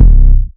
808 - Drop.wav